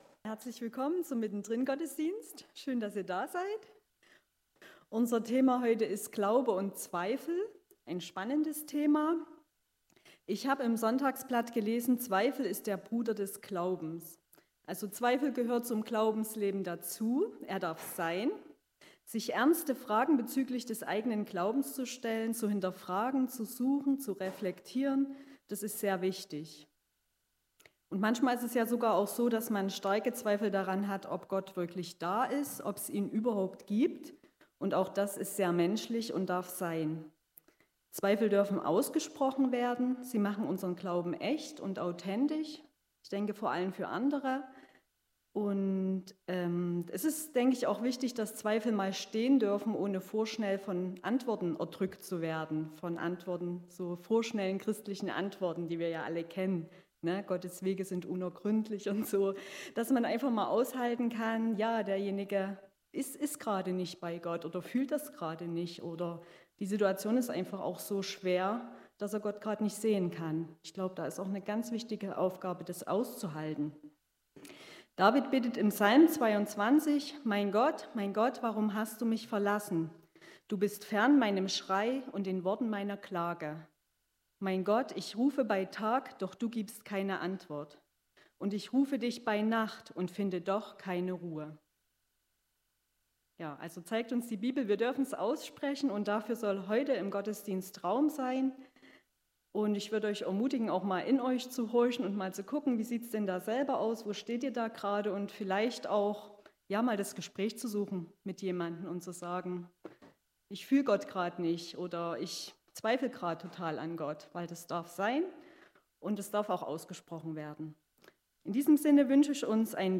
Beim Gottesdienst mittendrin haben 3 Frauen unserer Gemeinde Zeugnis zum Thema Glaube und Zweifel gegeben.